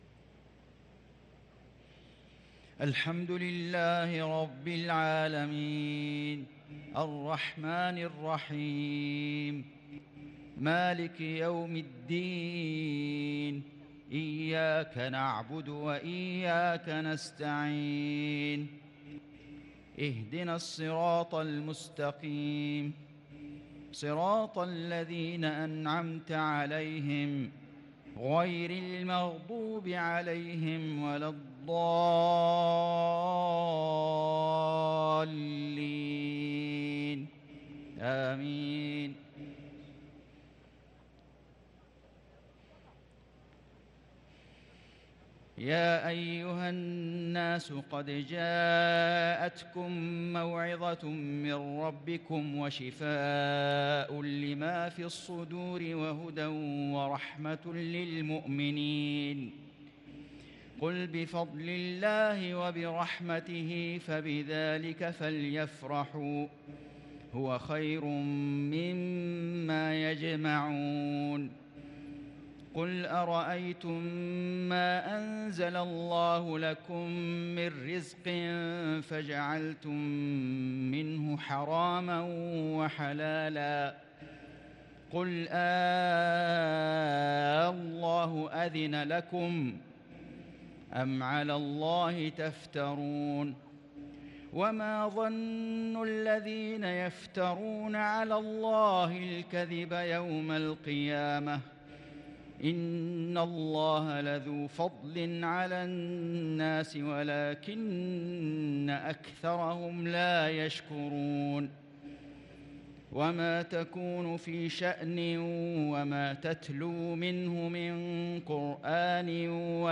صلاة العشاء ٥ ذو القعدة ١٤٤٣هـ من سورة يونس | Isha prayer from Surah Yunus 4-6-2022 > 1443 🕋 > الفروض - تلاوات الحرمين